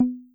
sfx_mute.wav